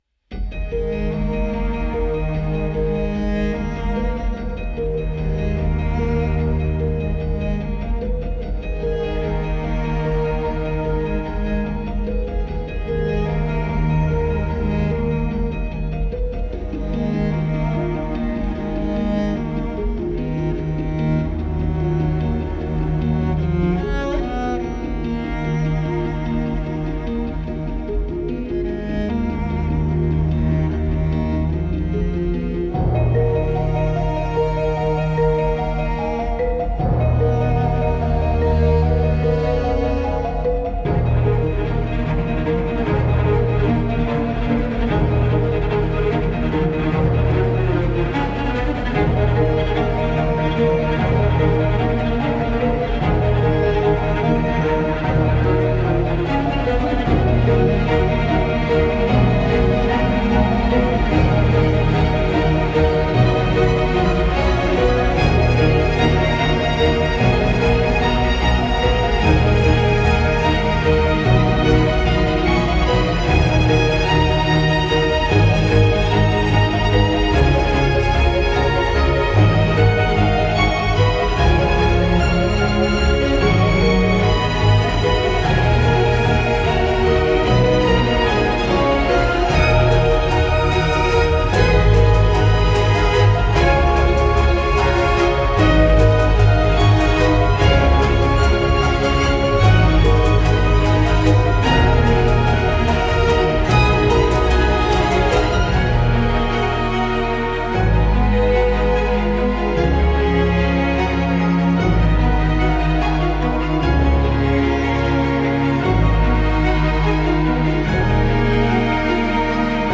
instrumental
موسیقی بیکلام